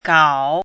chinese-voice - 汉字语音库
gao3.mp3